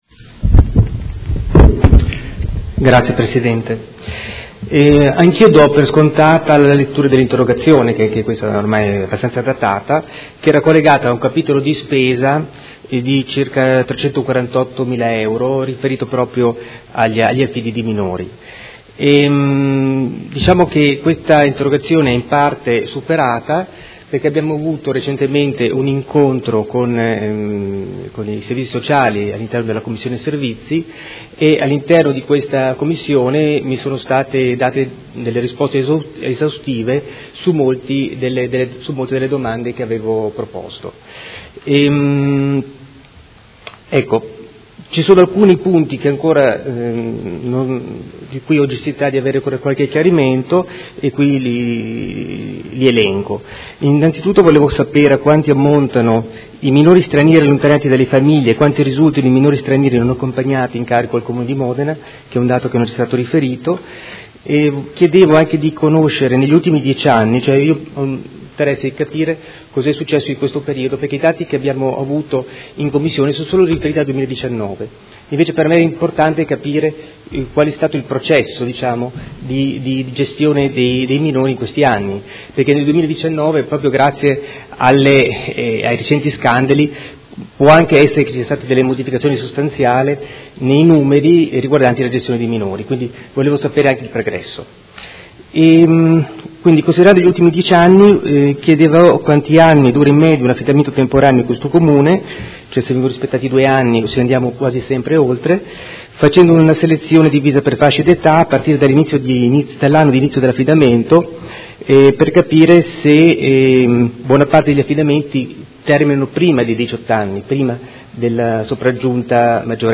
Giovanni Bertoldi — Sito Audio Consiglio Comunale